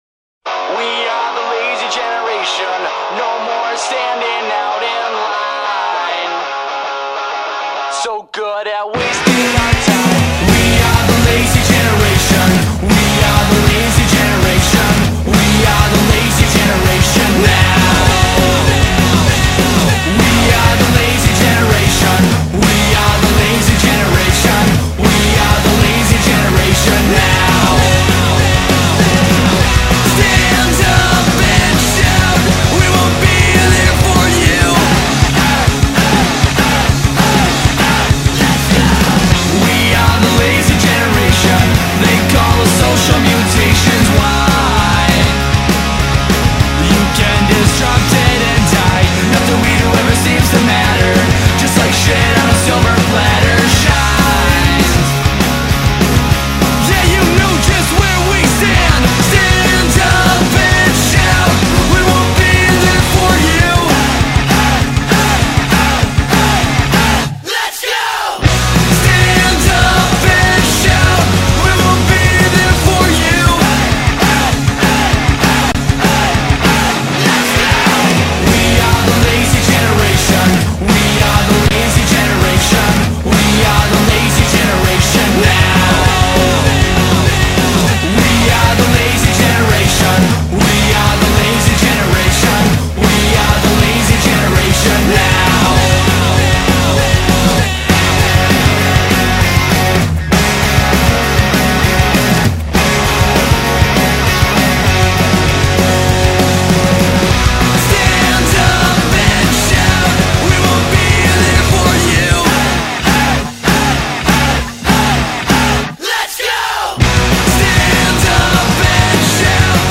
BPM99-198
Audio QualityCut From Video